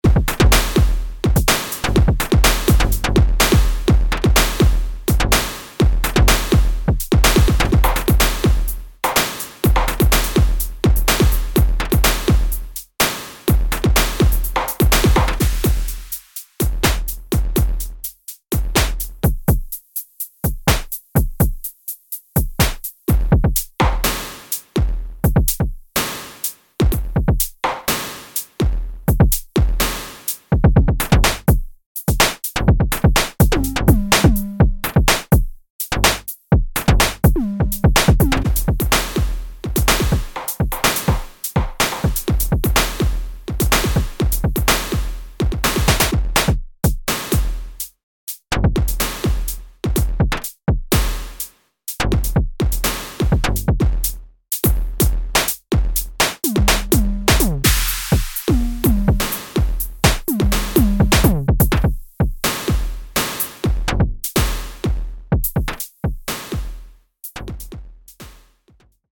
融合了白噪声、FM合成和失真效果的嘈杂合成鼓。